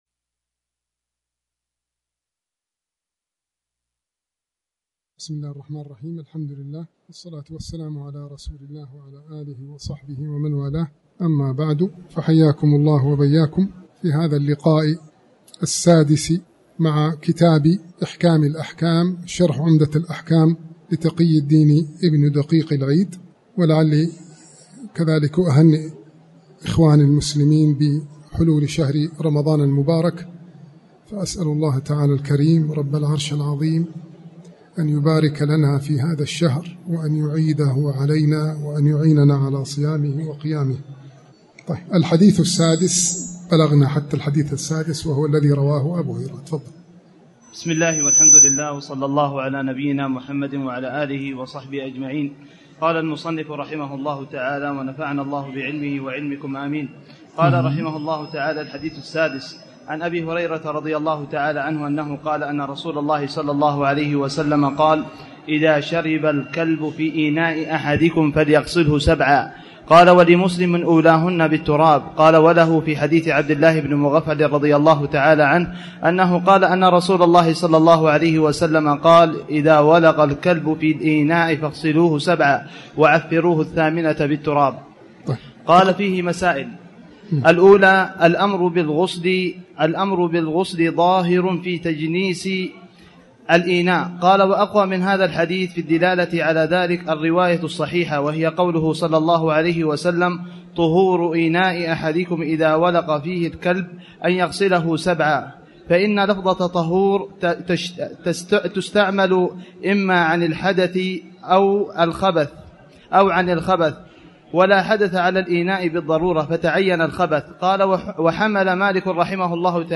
تاريخ النشر ١ رمضان ١٤٣٩ المكان: المسجد الحرام الشيخ